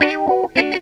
GTR 28 AM.wav